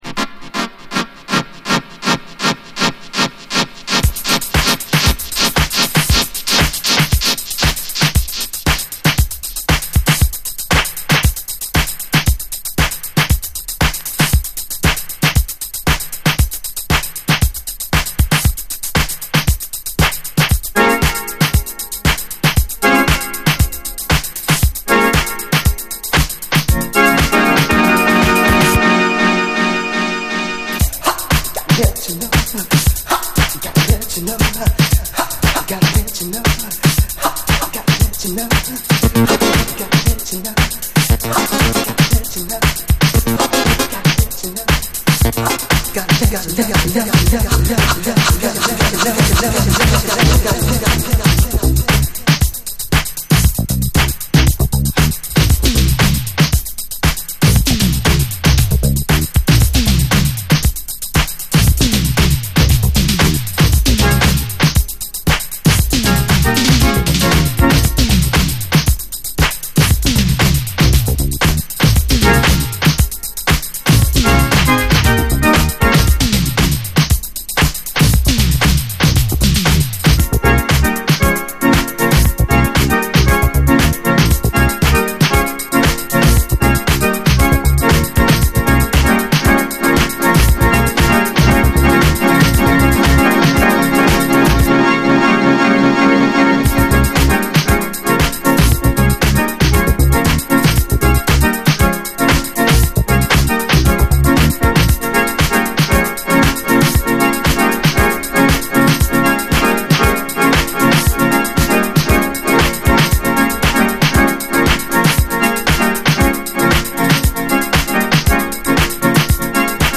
インストながらも尻上がりに熱を帯びるブラス＆ピアノ・ワークに盛り上がらざるをえない傑作フロア・チューン！